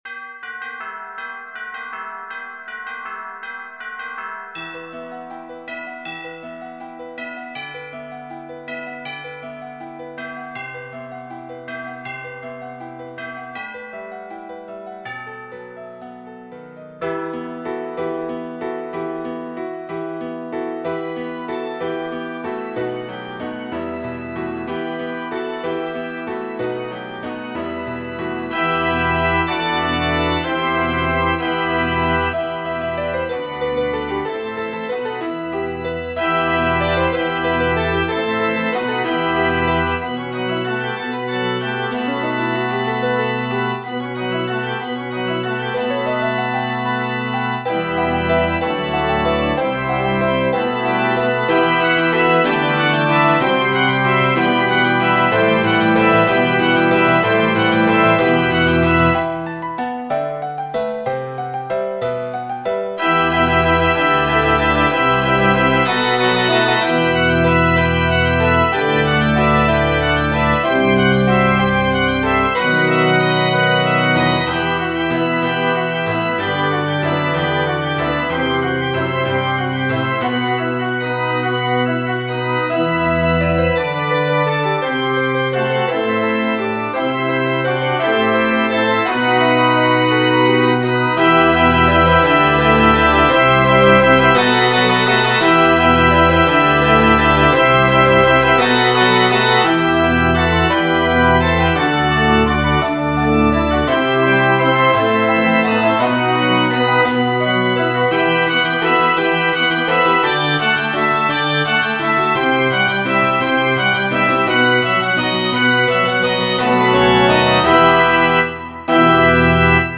Voicing/Instrumentation: Organ/Organ Accompaniment , Piano Duet/Piano Ensemble , Tubular Bells/Chimes We also have other 3 arrangements of " Carol of the Bells ".
Medley